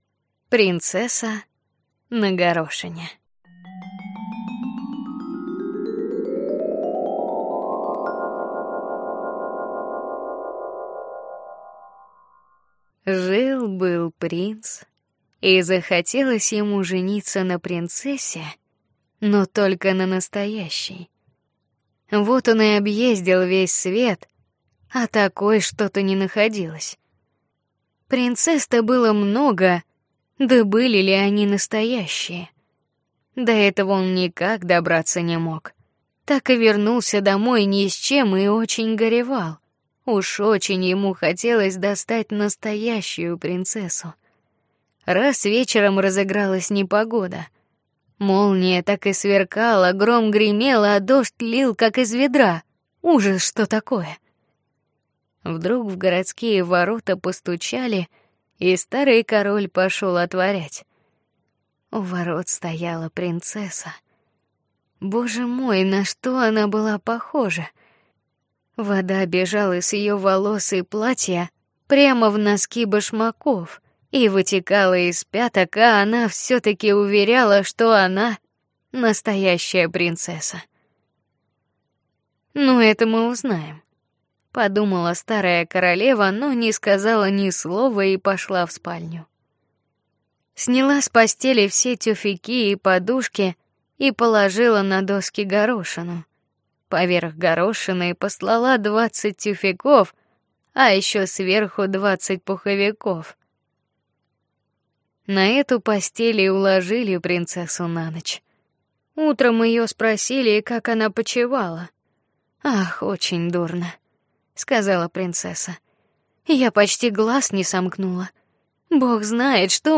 Аудиокнига Избранные сказки. Г.Х. Андерсен | Библиотека аудиокниг